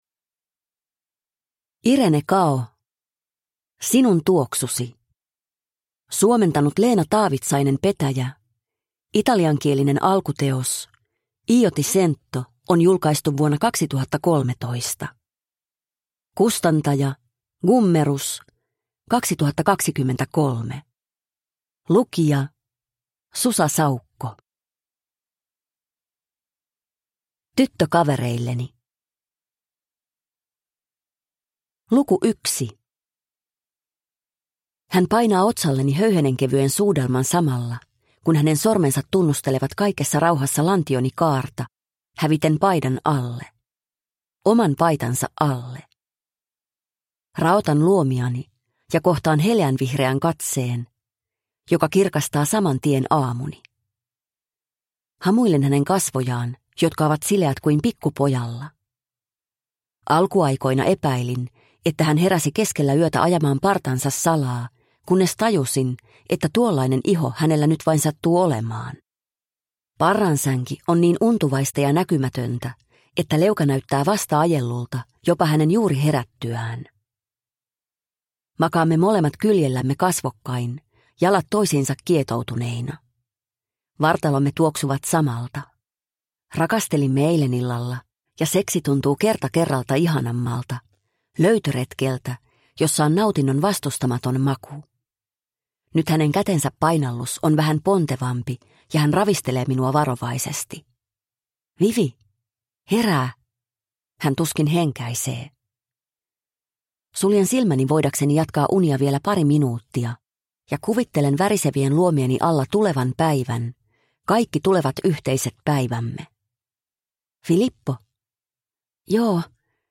Sinun tuoksusi – Ljudbok – Laddas ner